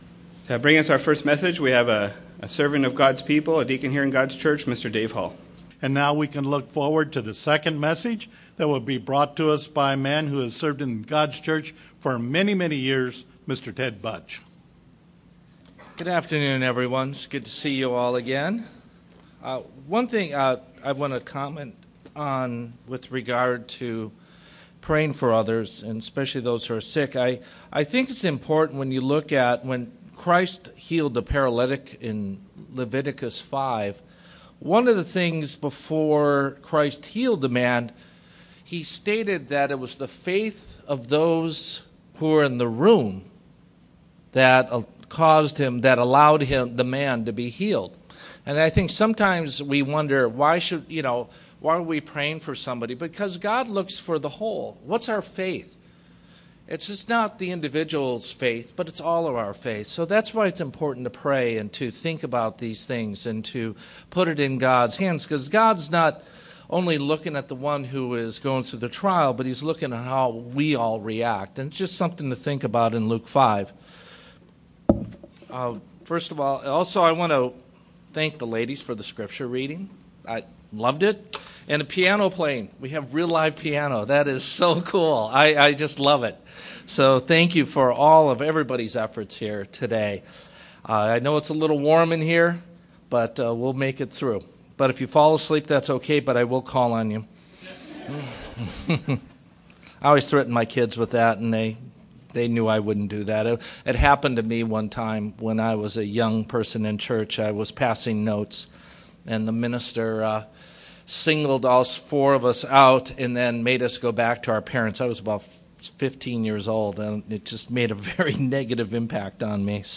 Given in Redlands, CA
UCG Sermon Studying the bible?